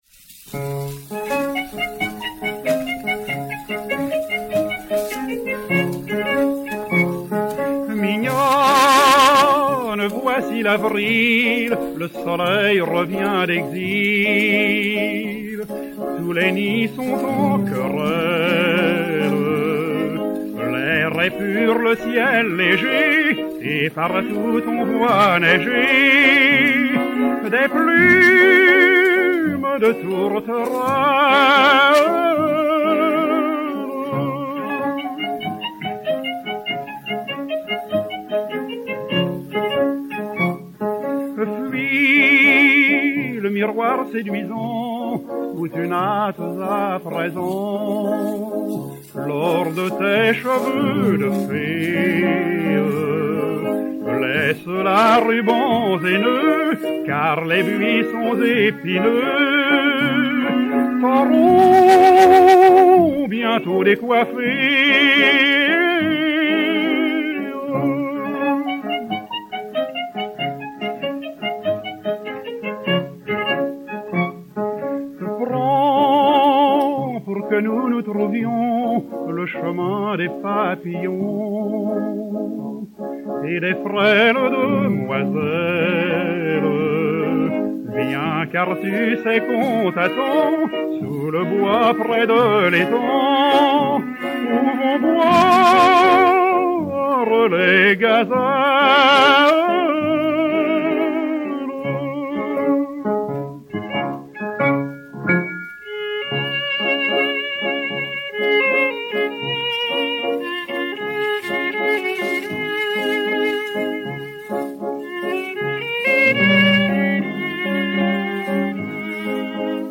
avec violon, violoncelle et piano Gaveau